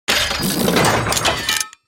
جلوه های صوتی
دانلود صدای ربات 43 از ساعد نیوز با لینک مستقیم و کیفیت بالا